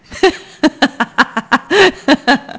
• A "classical" isolated laugh:
isol_laugh.wav